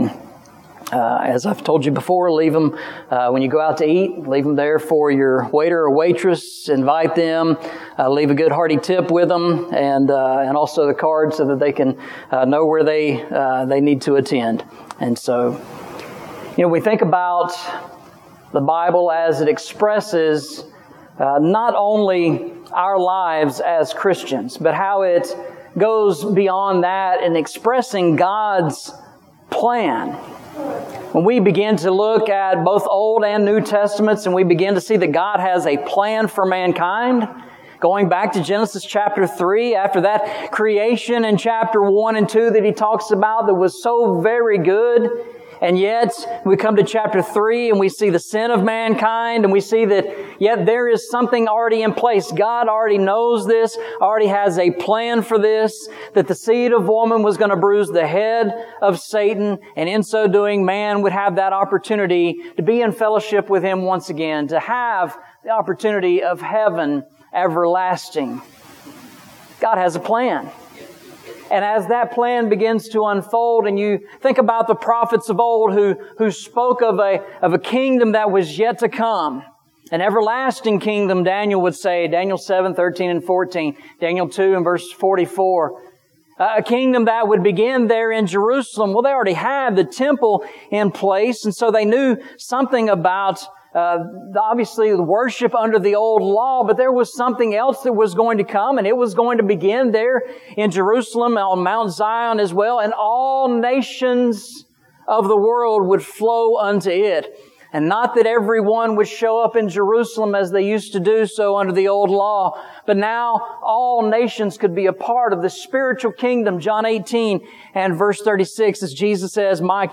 Eastside Sermons Passage: Colossians 1:18 Service Type: Sunday Morning « Walking Through the Bible